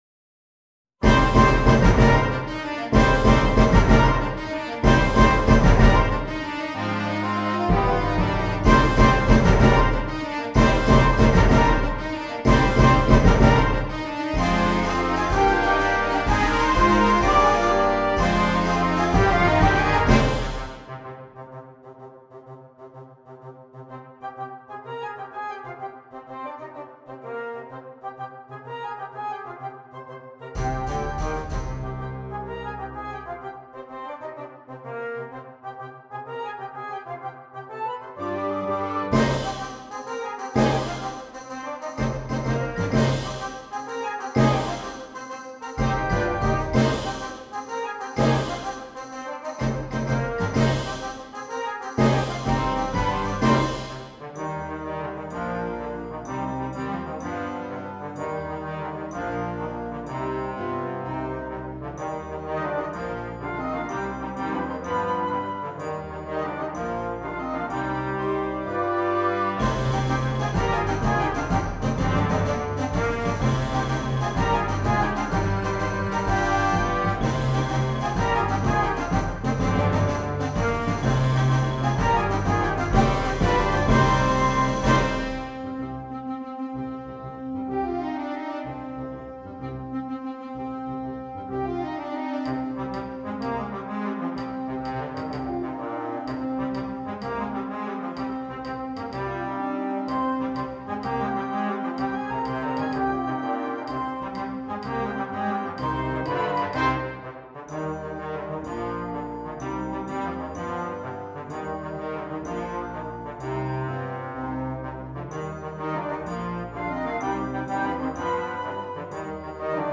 Flex Band